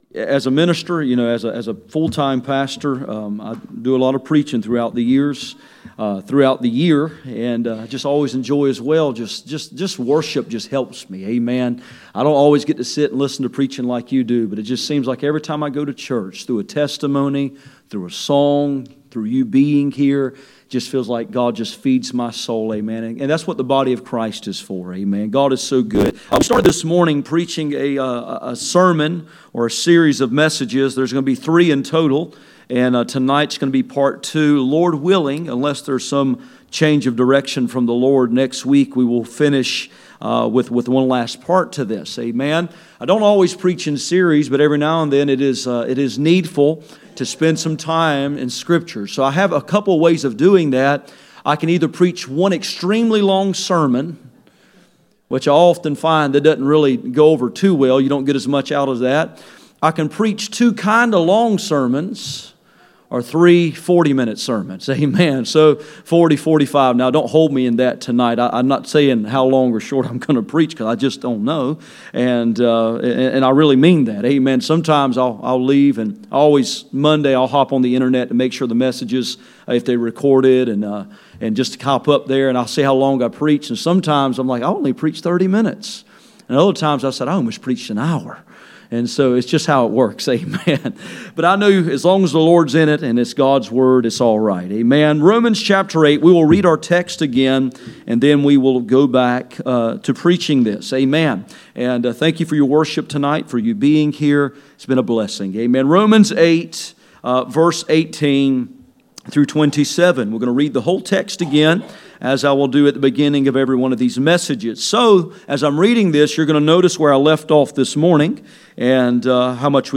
None Passage: Romans 8:18-27 Service Type: Sunday Evening %todo_render% « Grooming for Glory Grooming for Glory